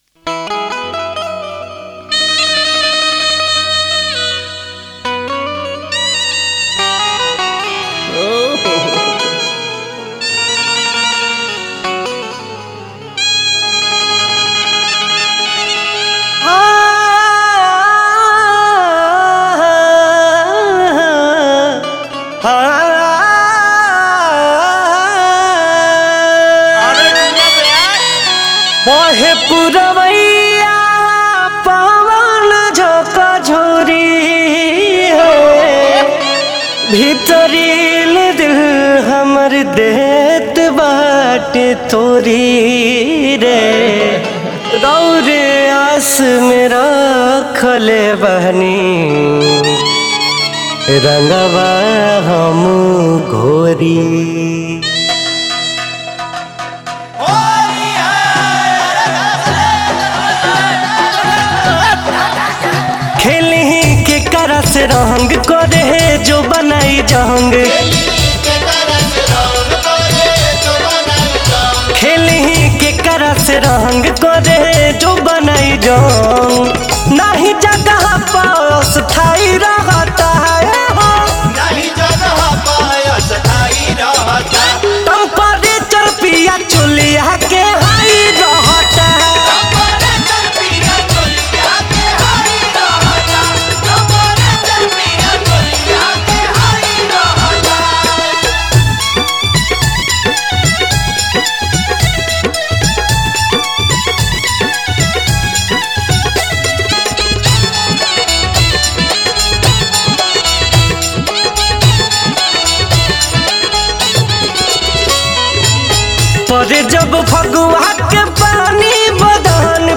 Bhojpuri Mp3 Songs